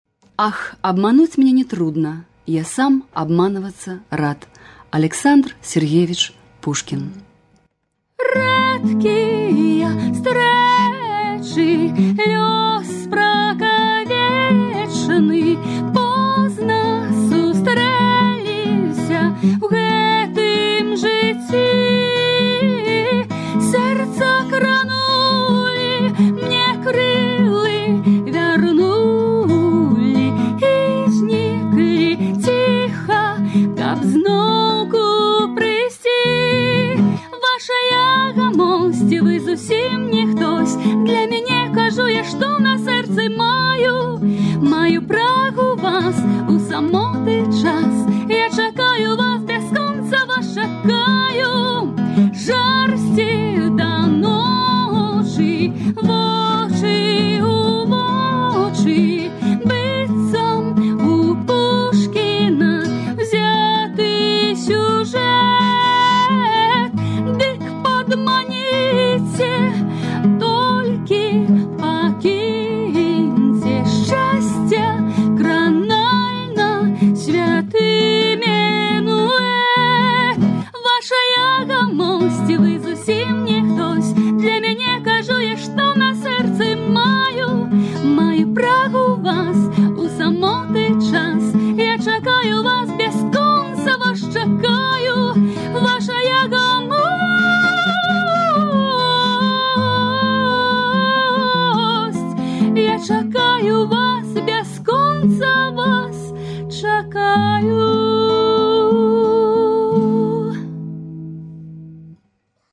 архіўны запіс